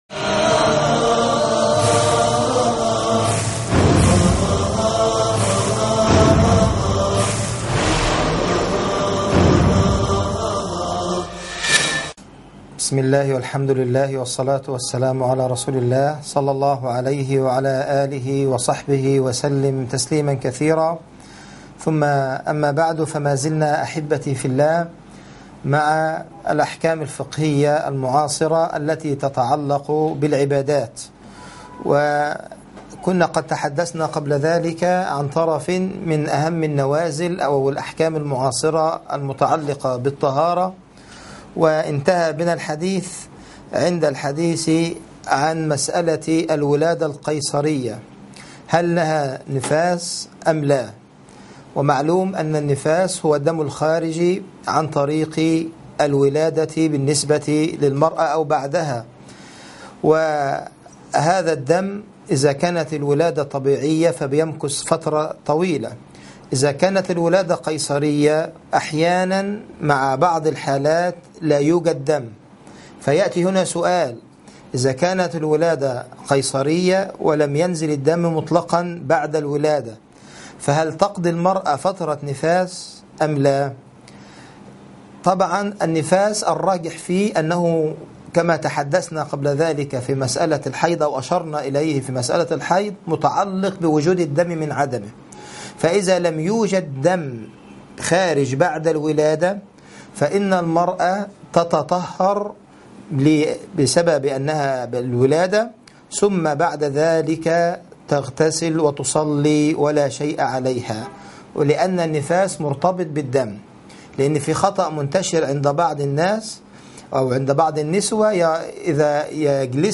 أحكام الطهارة - المحاضرة الرابعة